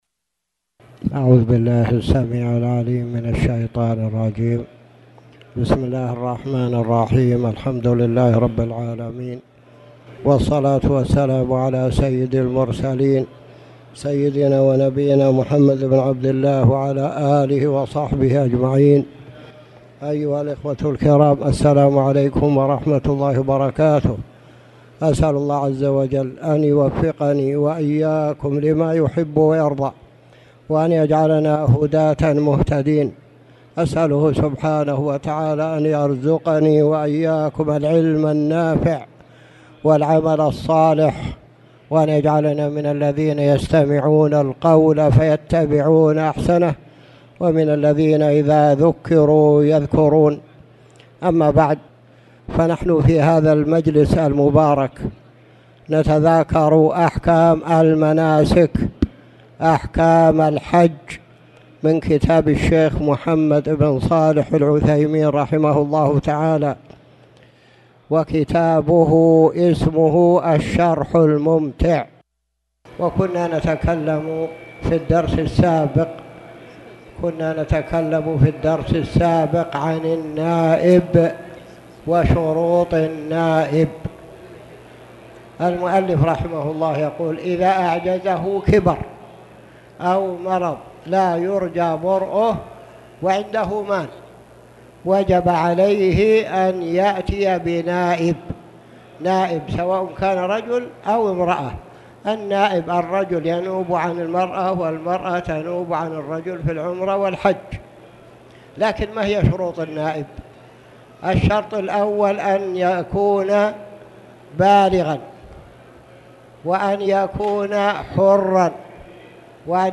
تاريخ النشر ٣ ذو القعدة ١٤٣٨ هـ المكان: المسجد الحرام الشيخ